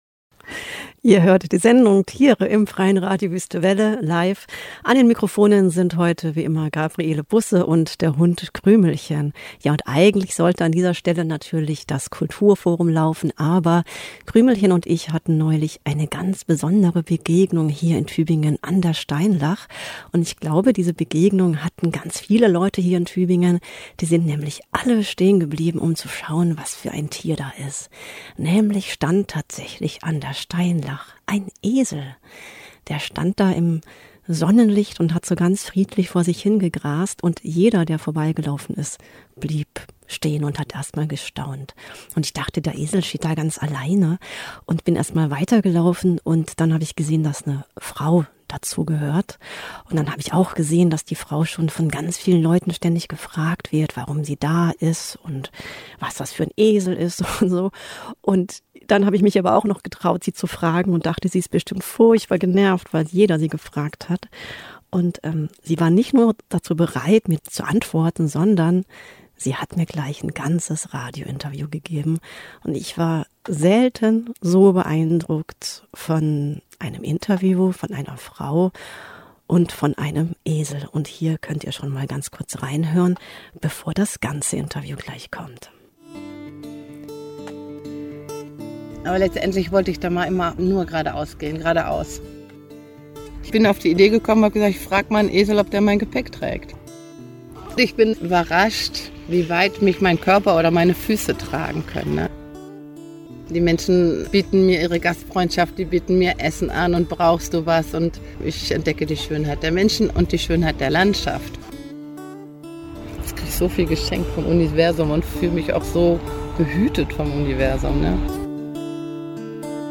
Ein ungewöhnliches Gespräch mit einem ungewöhnlichen Gespann.